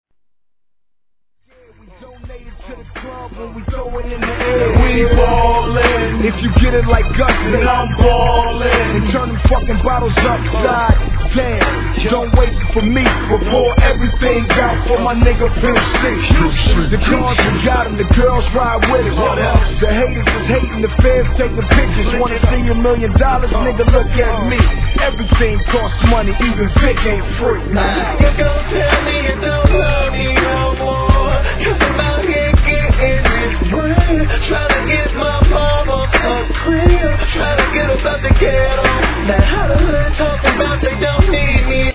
HIP HOP/R&B
哀愁漂う男性ヴォーカルとスロウエレクトロな上音が流れる近未来的なトラックが印象深い一曲!!